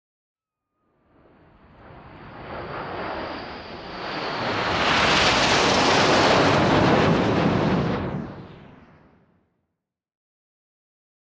Airplane Avro Vulcan flyby with air distortion turn jet